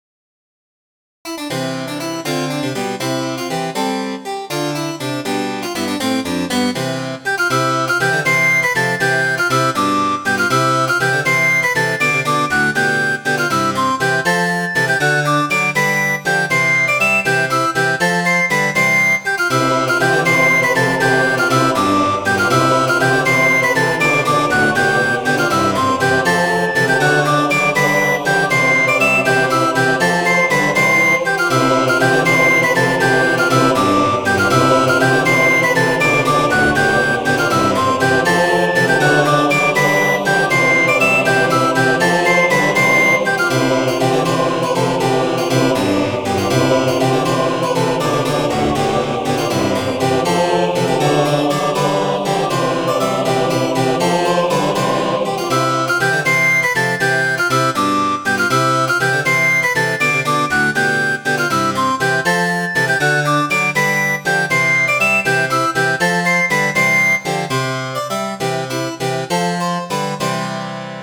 Midi File, Lyrics and Information to The Women All Tell Mey